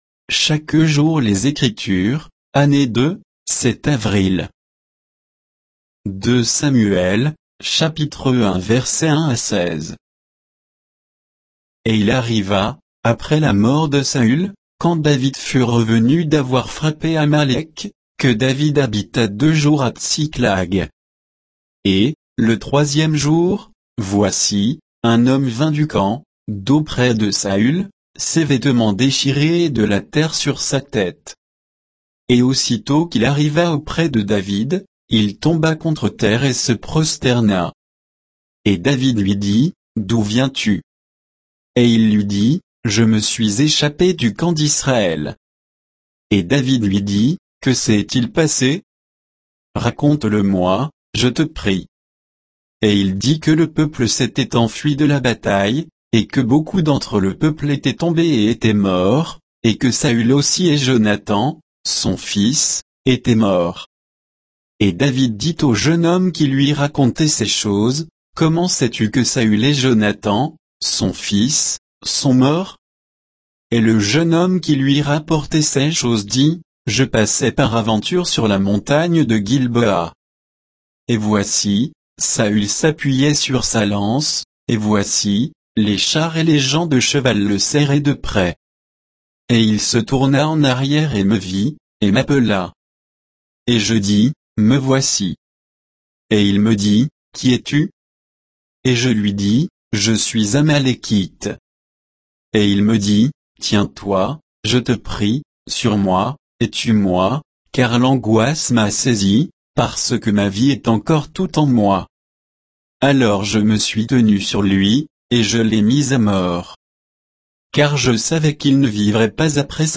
Méditation quoditienne de Chaque jour les Écritures sur 2 Samuel 1, 1 à 16